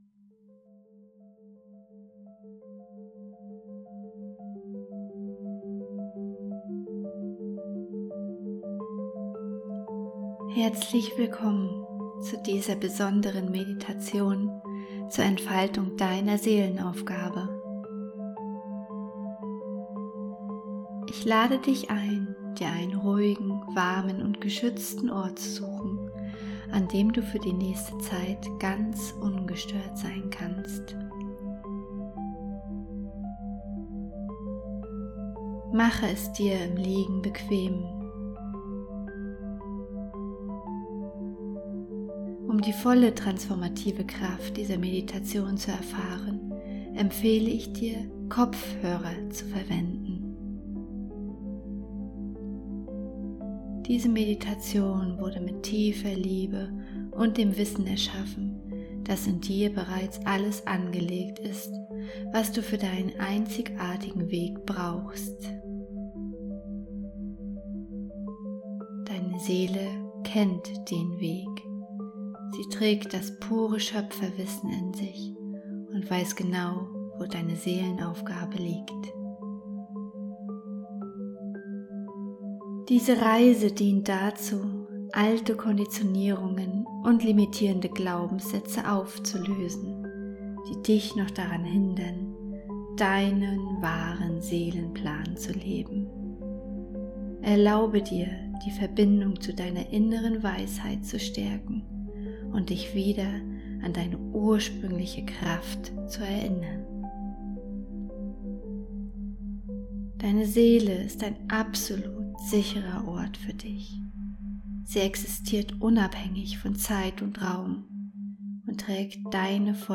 Meditation zum Seelenplan
Wichtige Hinweise: Nutze unbedingt kabelgebundene Kopfhörer , damit die binauralen Beats ihre volle Wirkung entfalten können.
Der summende Ton im Hintergrund ist der Binaurale Ton, der dein Gehirn in den Theta-Zustand bringt